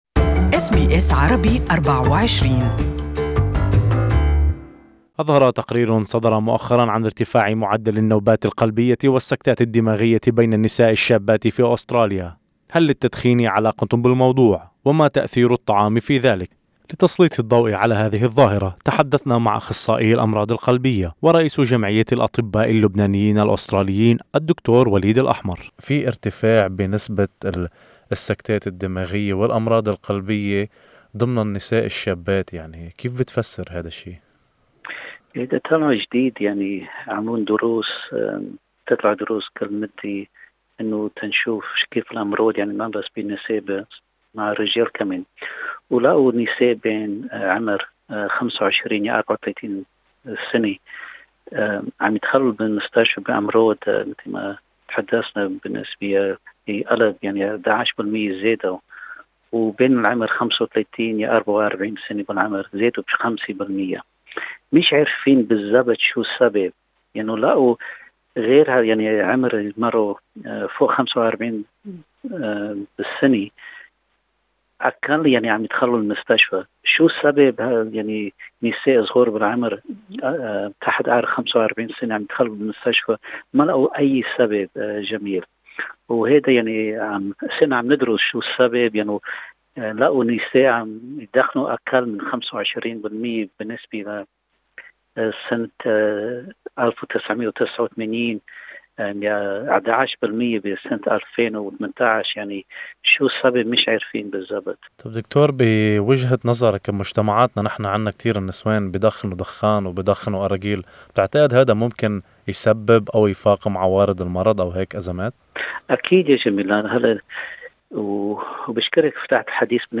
ولتسليط الضوء على هذا الموضوع تحدثنا مع أخصائي الأمراض القلبية